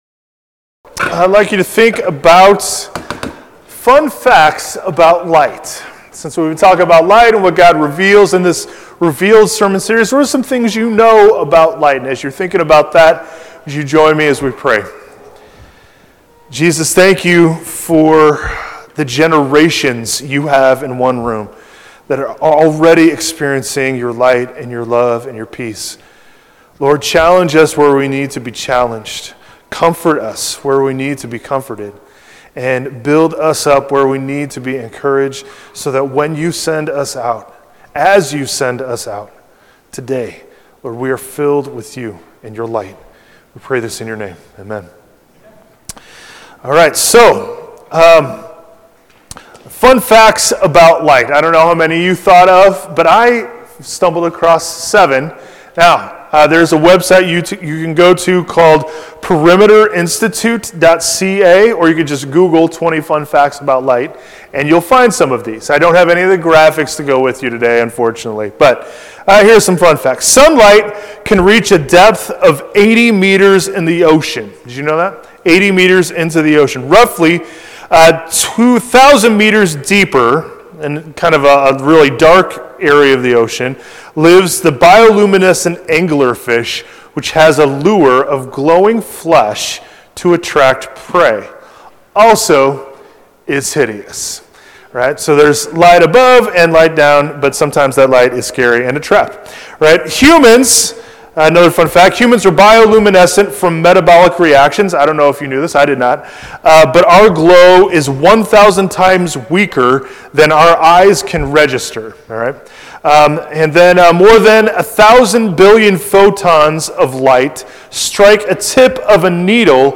February-8-2026-sermon.mp3